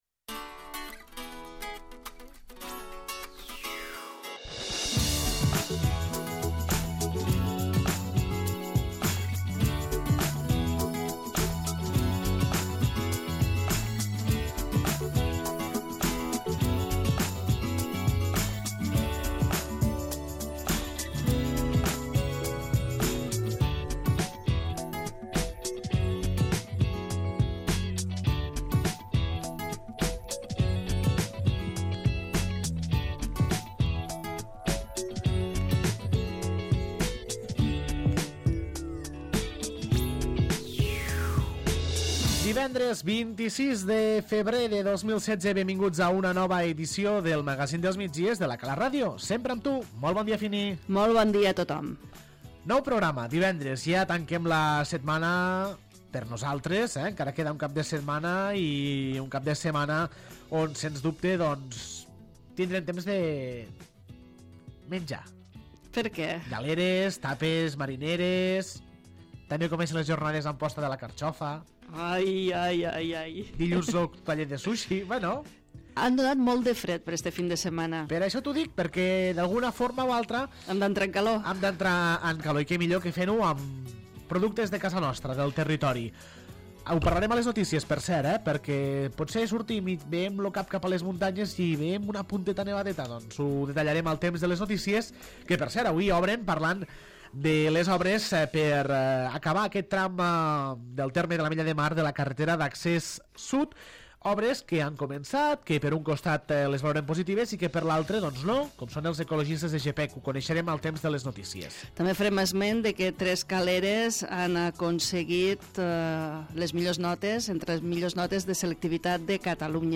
Segona part del programa amb l'entrevista a l'alcalde de l'Ametlla de Mar, Jordi Gaseni, repassant l'actualitat municipal dels darrers dies.